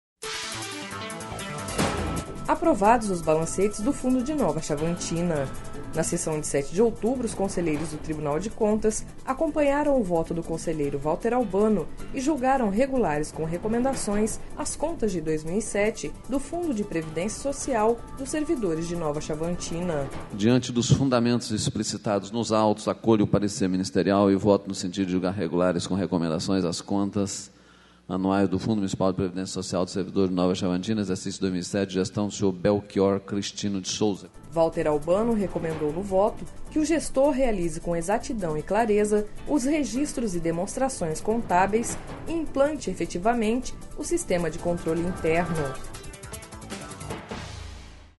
Sonora: Valter Albano – conselheiro do TCE-MT